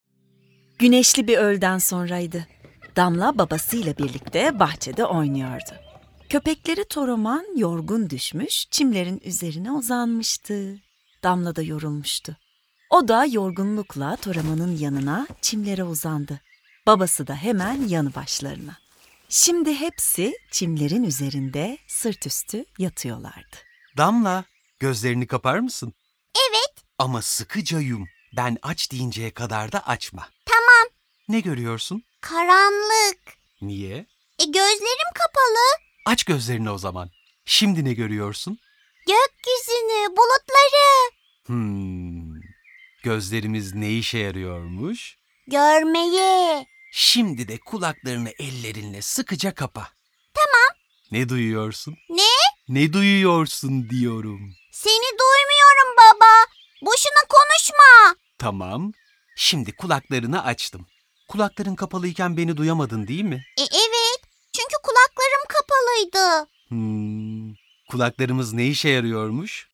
Damla Beş Duyumuzu Öğreniyor sesli tiyatrosu ile eğlenceli ve eğitici bir maceraya çıkın.